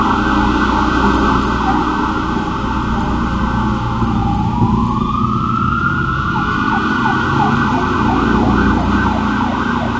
Звук сирены
Еще одна скорая: